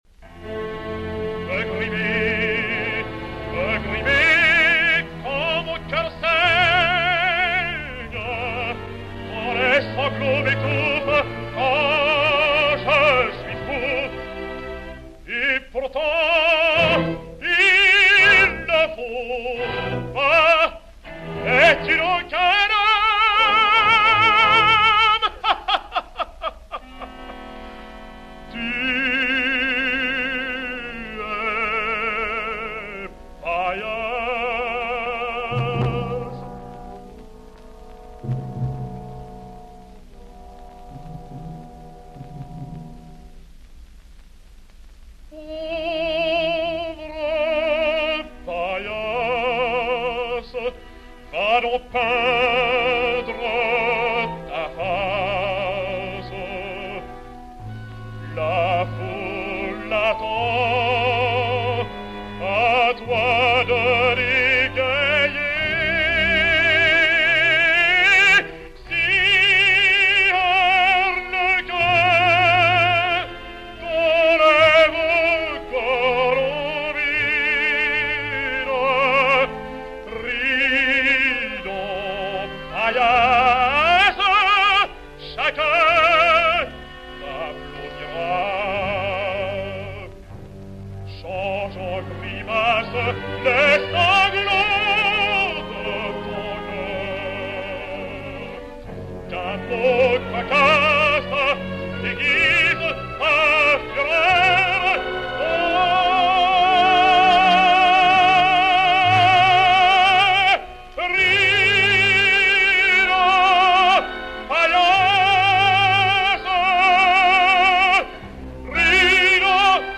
Encore plus rarement papa va chercher au grenier la valise de l’antique gramophone à manivelle et les quelques 78 tours qui ont sa faveur.
Georges Thill chanter Pauvre Paillasse sans éprouver une profonde et très sincère émotion.
georges_thill-pauvre_paillasse-leoncavallo.mp3